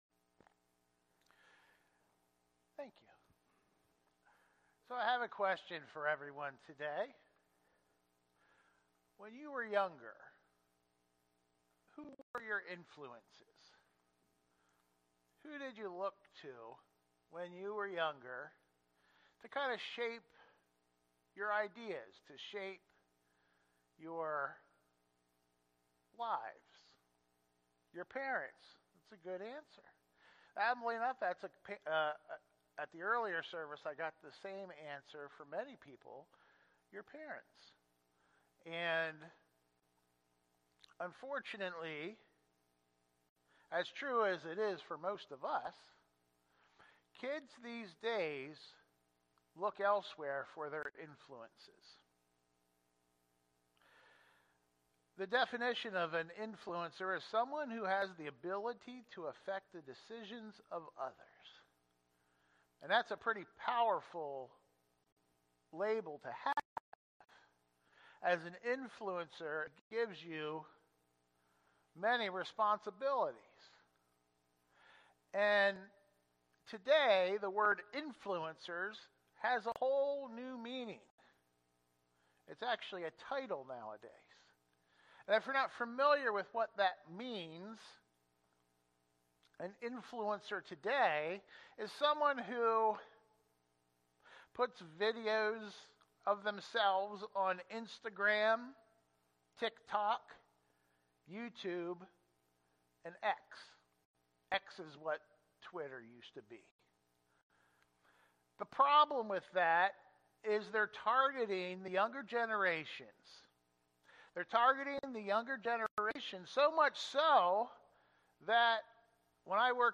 Sermons | Columbia Church of God